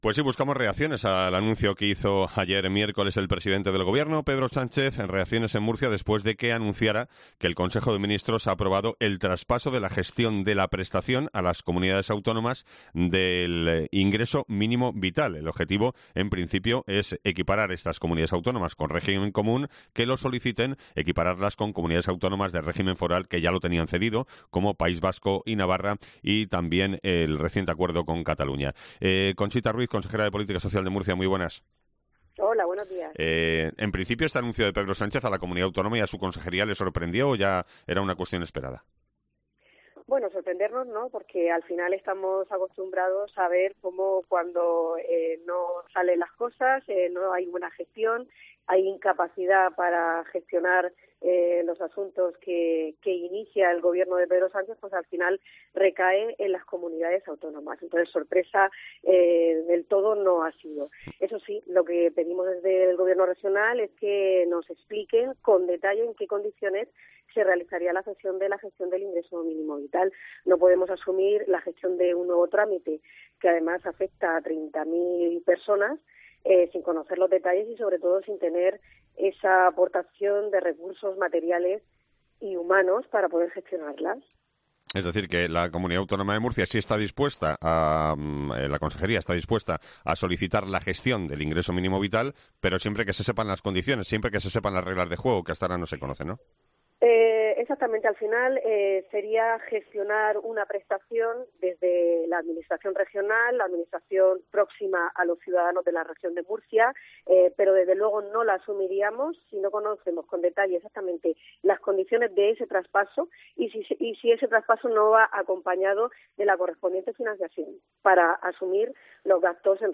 Conchita Ruiz, consejera de Política Social
Son palabras esta mañana en COPE de la consejera de Política Social Conchita Ruiz después del anuncio hecho ayer por el presidente del Gobierno Pedro Sánchez, quien afirmó que las comunidades que lo soliciten podrán gestionar esta prestación al igual que ya lo hacen las de régimen foral como Pais Vasco o Navarra.